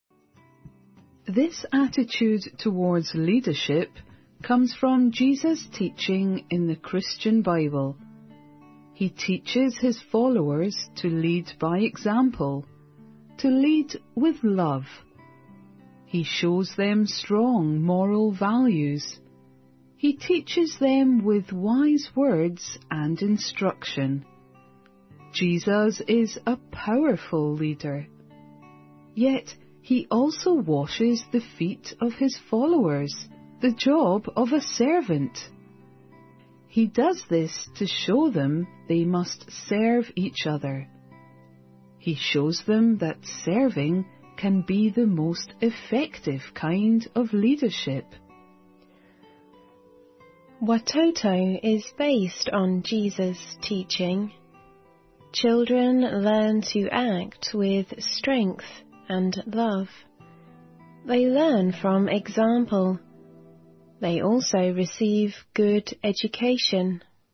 环球慢速英语 第528期:世界艾滋病日—领导力(6)